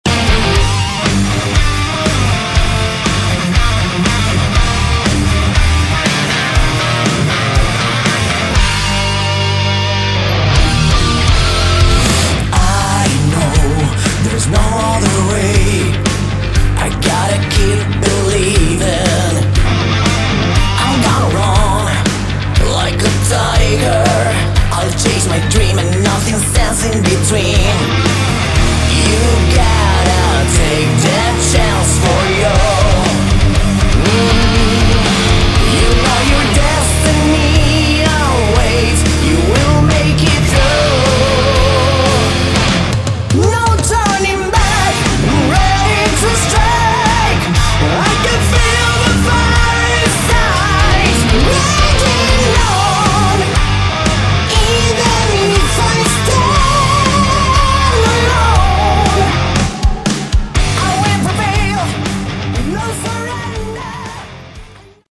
Category: Melodic Metal
Vocals
Guitar
Bass
Drums